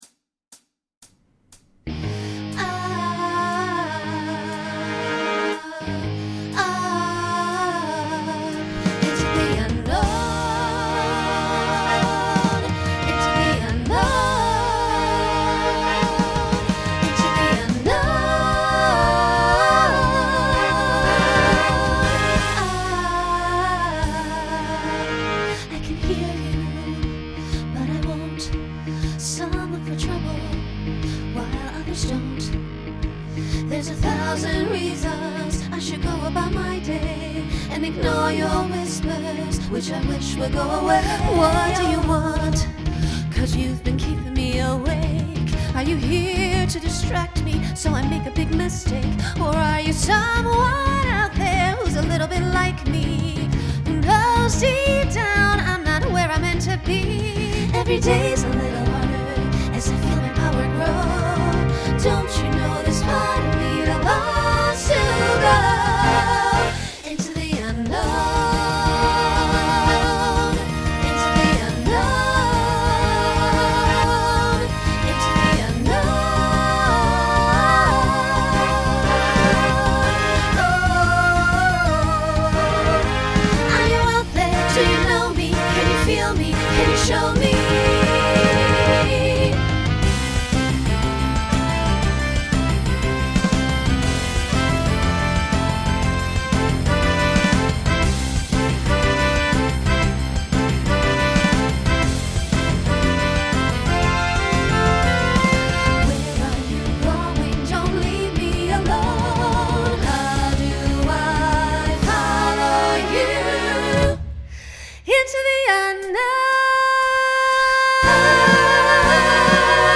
New SATB voicing for 2026.